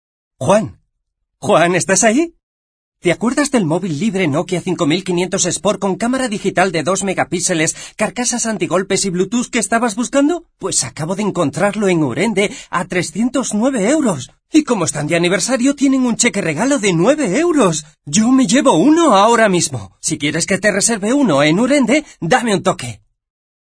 Voces Masculinas